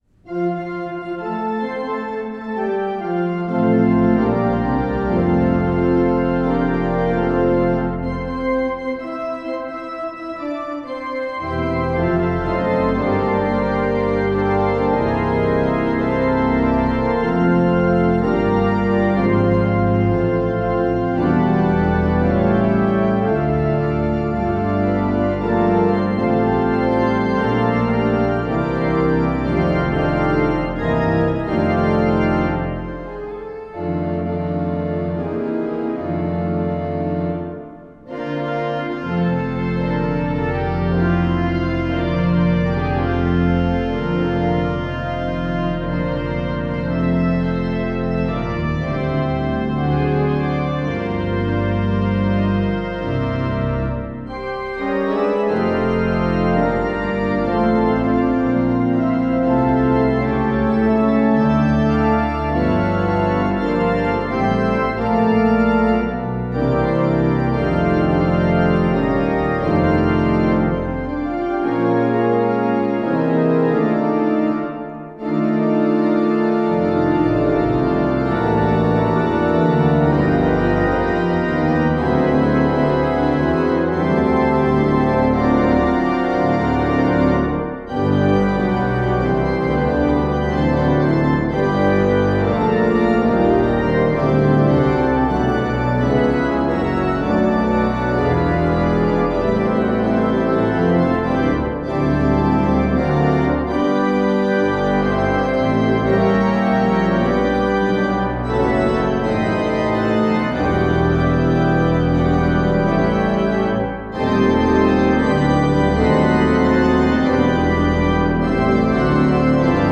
Voicing: Organ 3-staff